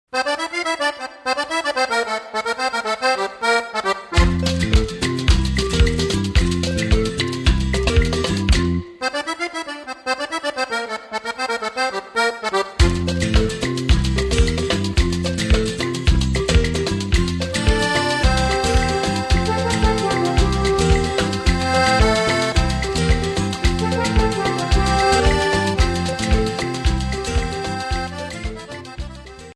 Ballabili Sudamericani
Rumba